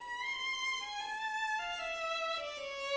Finale: Allegro molto 2/2 D major
Excerpt 15 (violin)